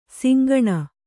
♪ singaṇa